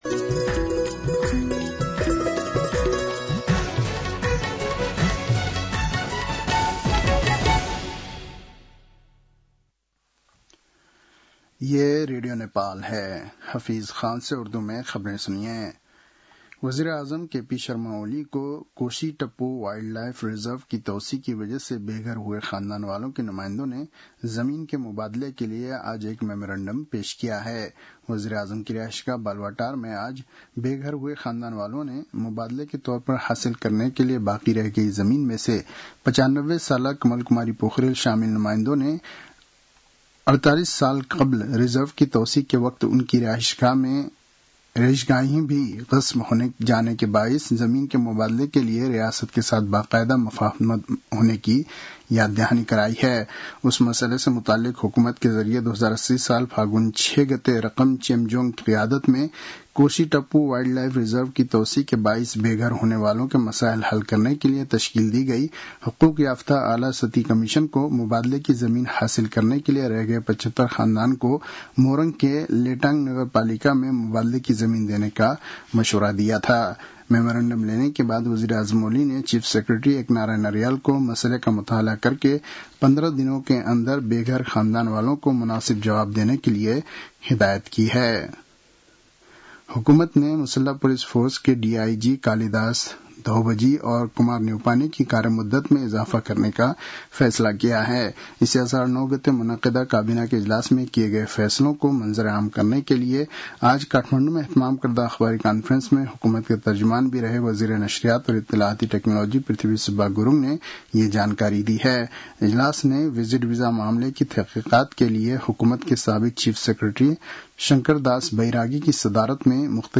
उर्दु भाषामा समाचार : ११ असार , २०८२